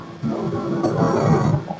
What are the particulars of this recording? Â I used the induction loop receiver to listen in to the sound of my computer.